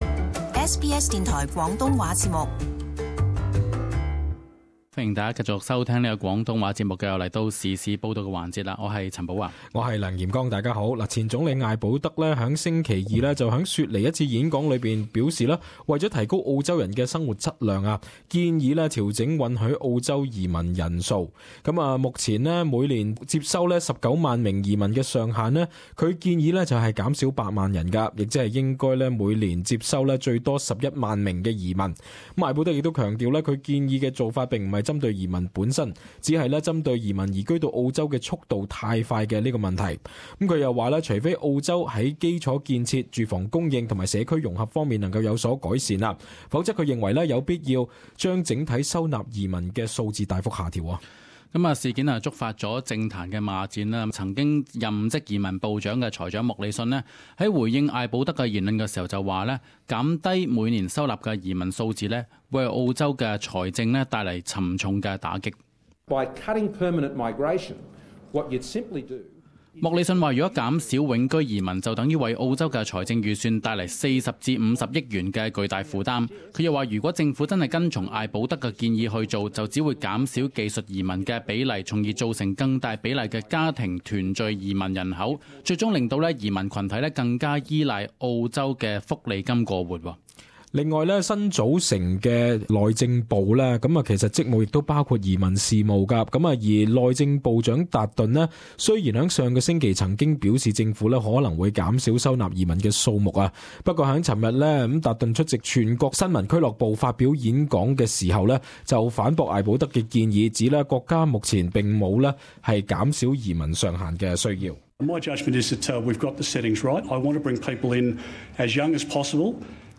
【时事报导】艾保德「减低移民上限论」惹党内领导层关注